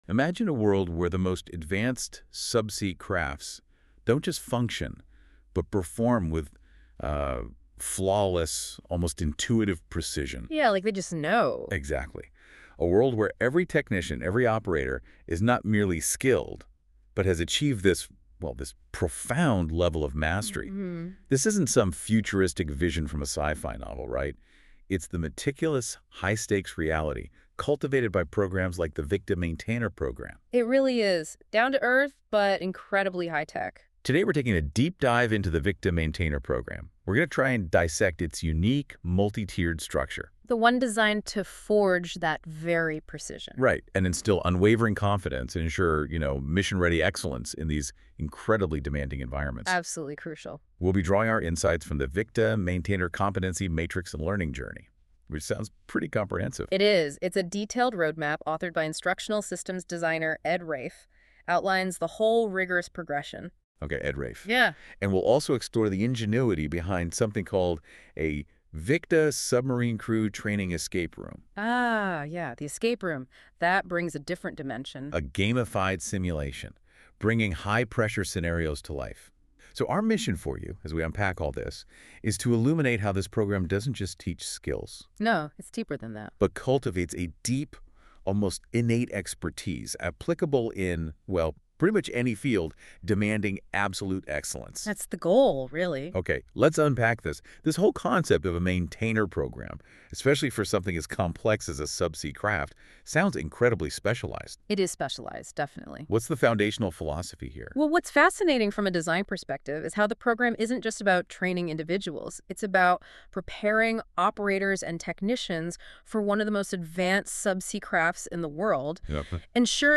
From Apprentice to Master — Audio Briefing